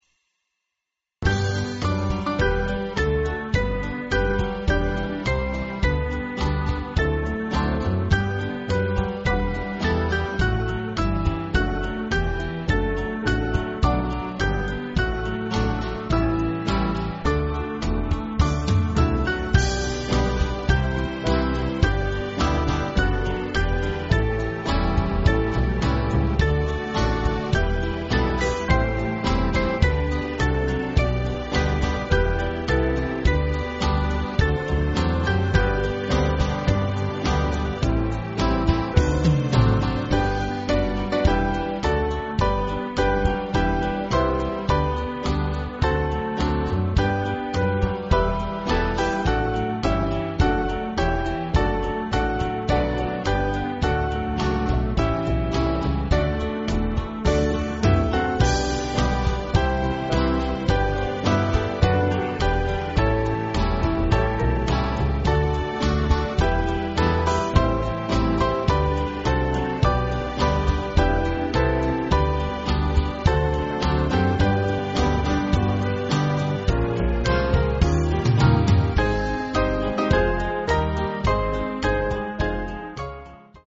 Small Band
(CM)   3/G-Ab